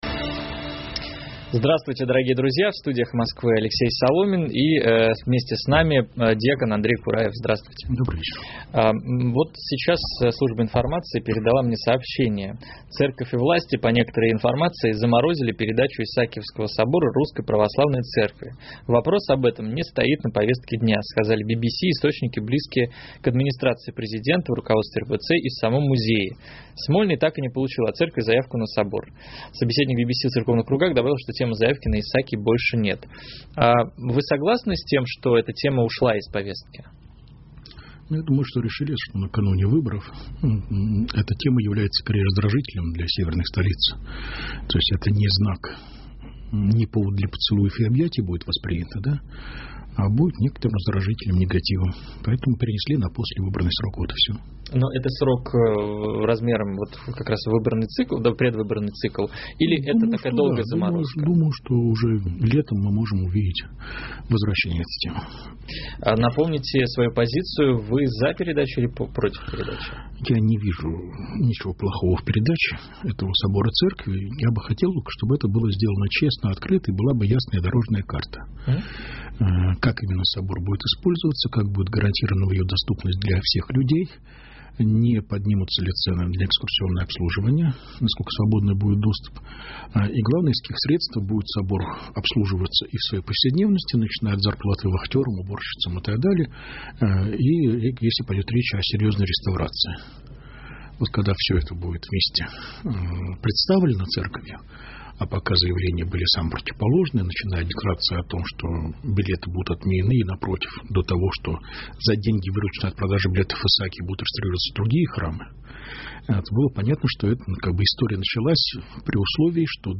Вместе с нами дьякон Андрей Кураев.